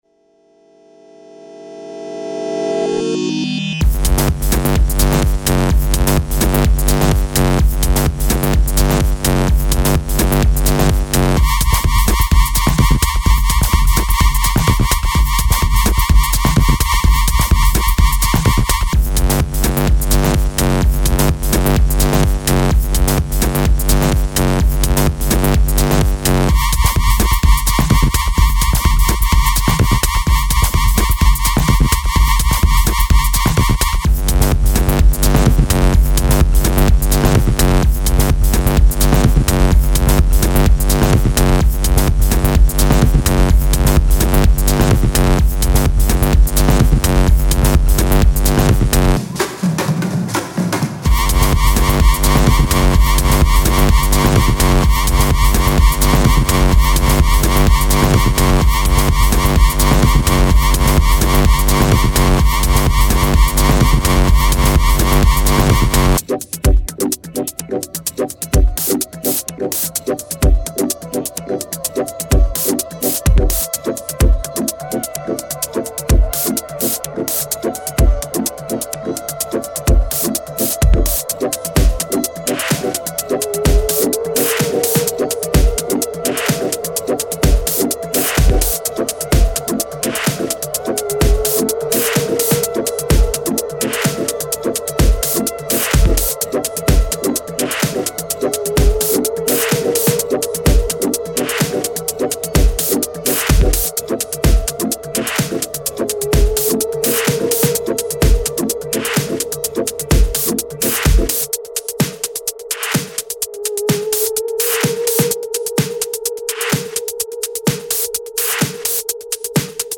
Trance
Trip-hop
Electro